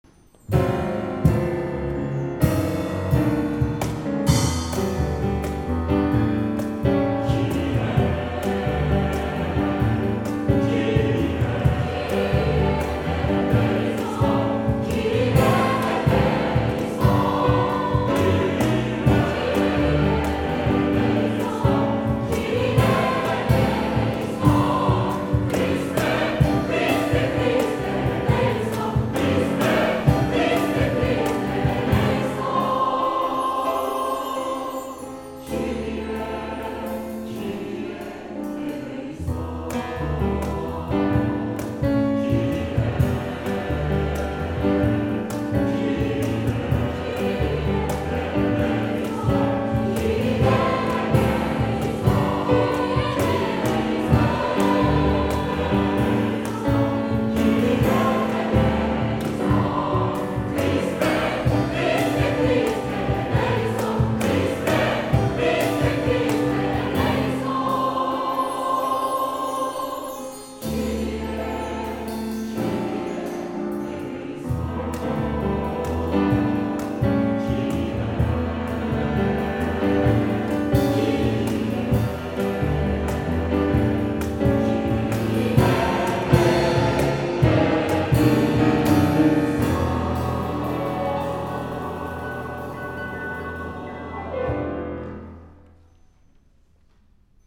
参考音源を付けましたので、リズム感の参考にして下さい。